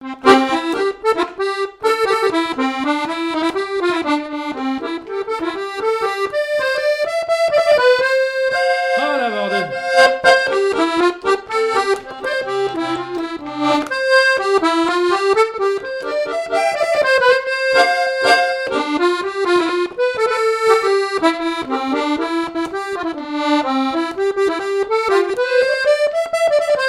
danse : branle : avant-deux
répertoire de chansons, et d'airs à danser
Pièce musicale inédite